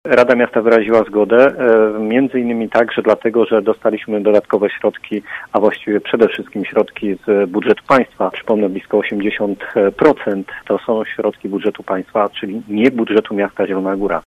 Przewodniczący Rady Miasta przedstawia argumenty za wybudowaniem obwodnicy południowej Zielonej Góry.
Tłumaczy Piotr Barczak: